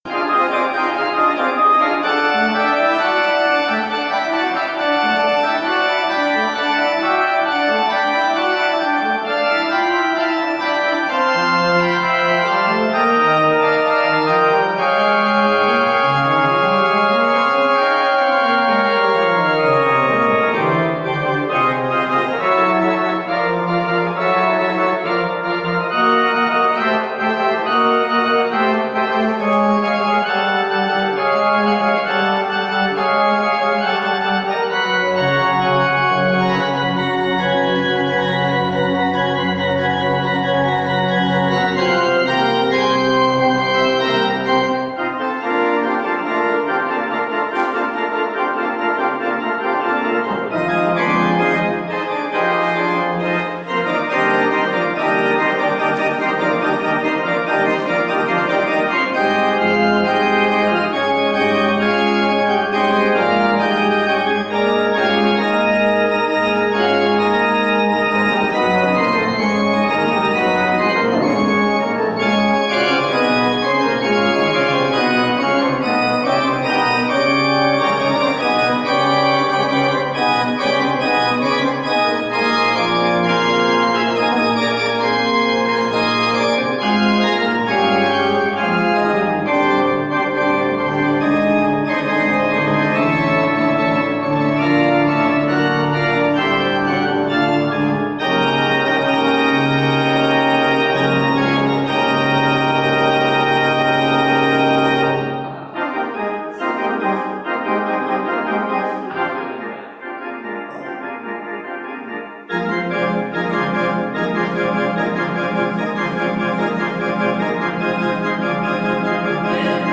Audio recording of the 10am service